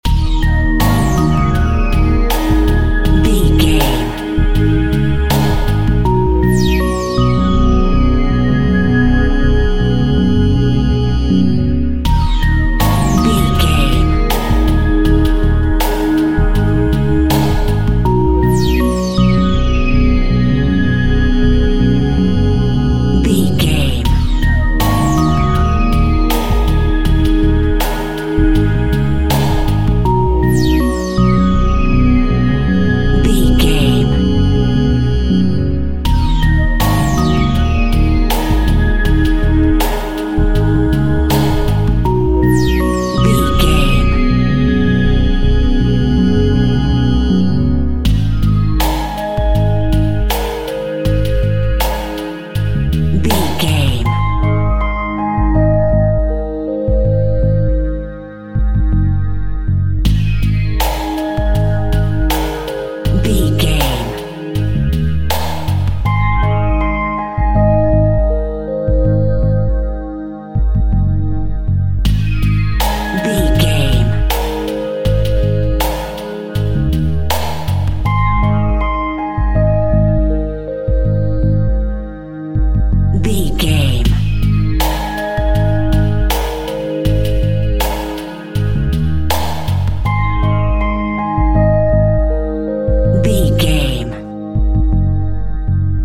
Cool Industrial Music.
Aeolian/Minor
E♭
Slow
futuristic
hypnotic
mechanical
dreamy
tranquil
synthesiser
bass guitar
drum machine
ambient
downtempo
pads
dark